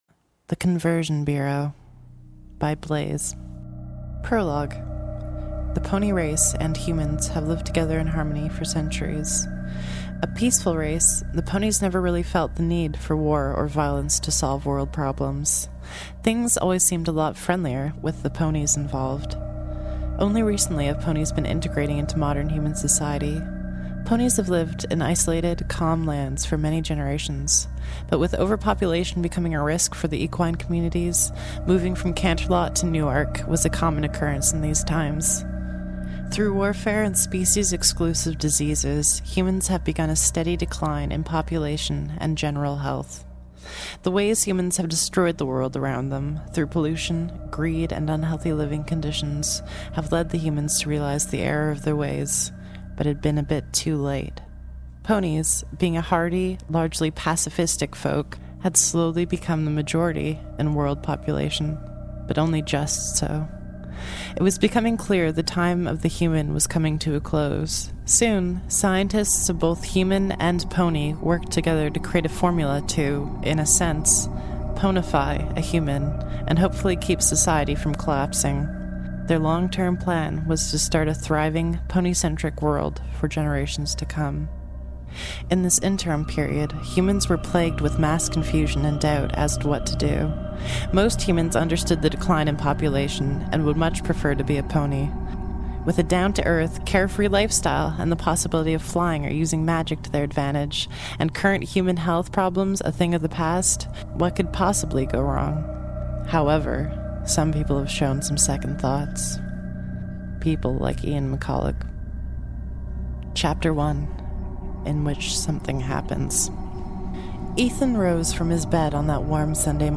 Meet Ethan, an insecure 17 year old boy in a changing world. An audiobook style live reading of compelling My Little Pony: Friendship is Magic fanfiction.